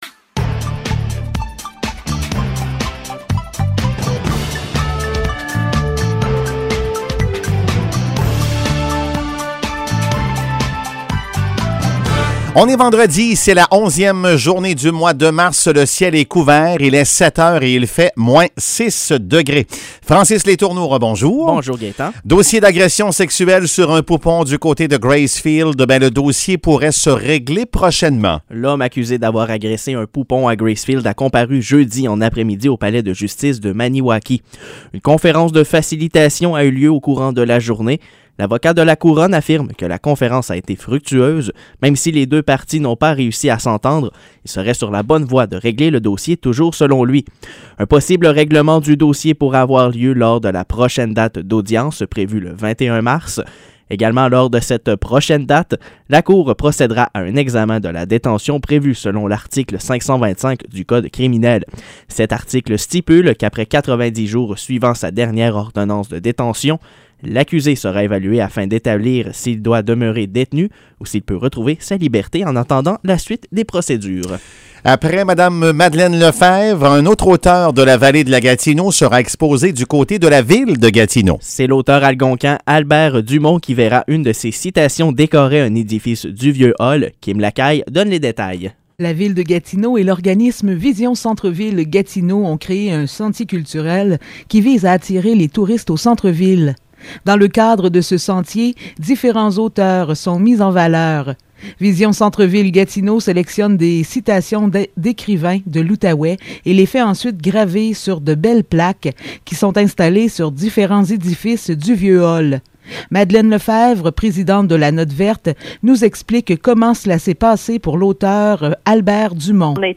Nouvelles locales - 11 mars 2022 - 7 h